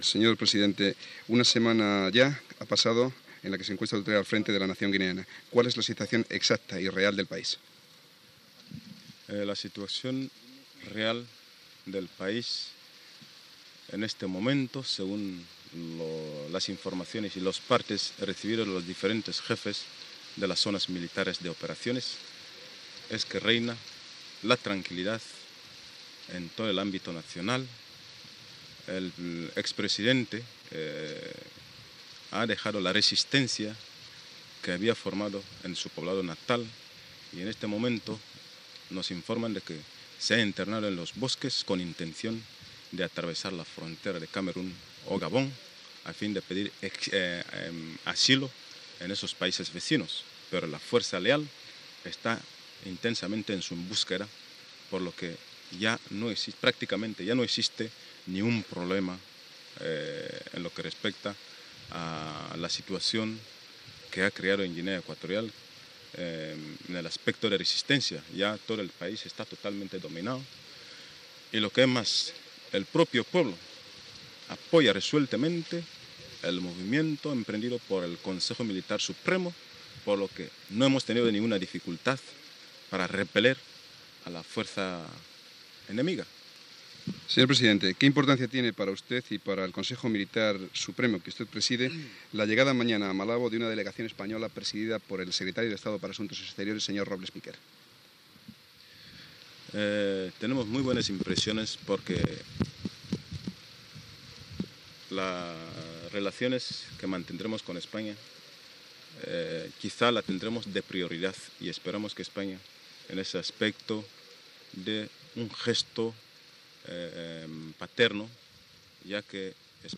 Entrevista alpresident de Guinea Equatorial, el tinent coronel Teodoro Obiang, que havia deposat al dictador Francisco Macías Nguema el 3 d'agost de 1979
Informatiu
Extret del programa "El sonido de la historia", emès per Radio 5 Todo Noticias el 29 de setembre de 2012